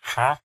mob / villager / haggle2.ogg
haggle2.ogg